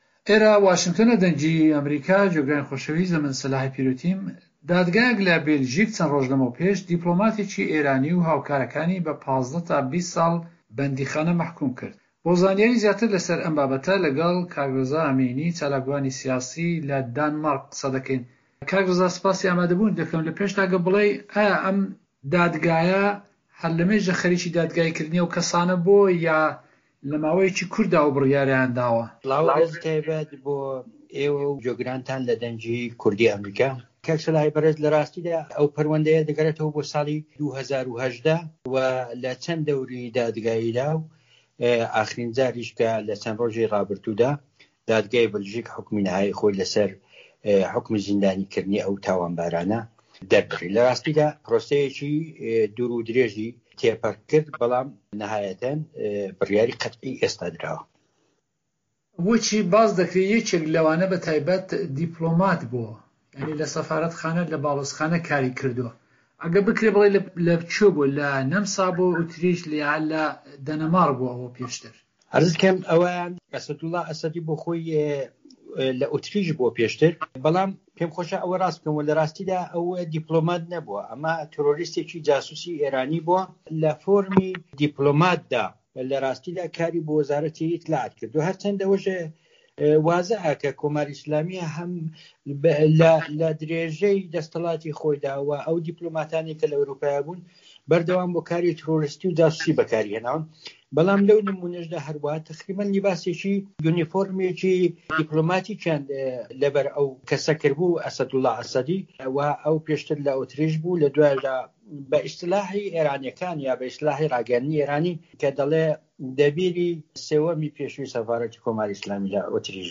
وتووێژی